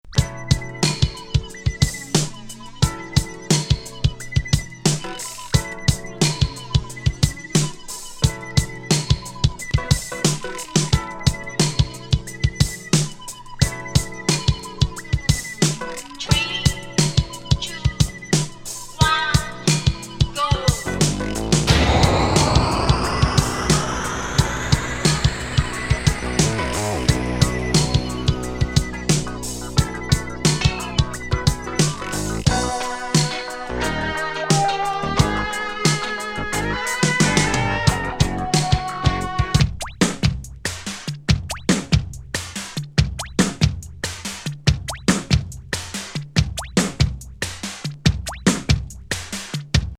スペーシー・イントロ